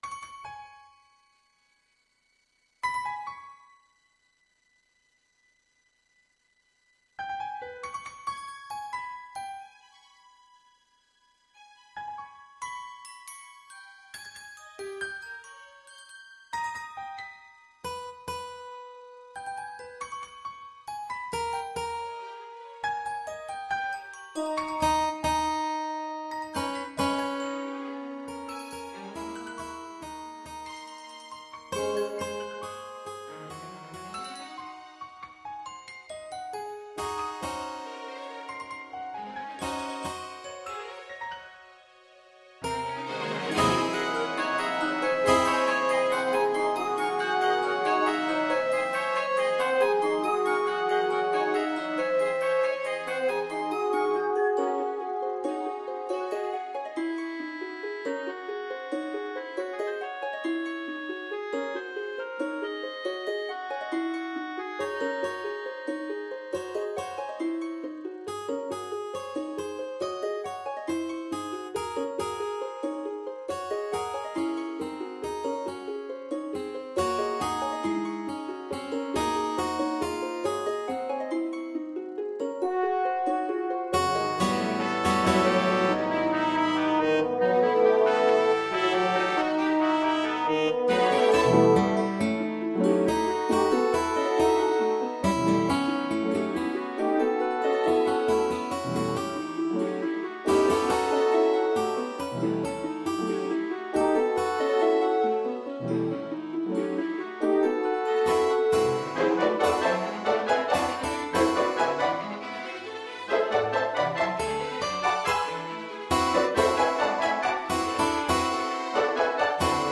for Orchestra and Chorus
timp; 3 perc; pno; harp; st SATB Chorus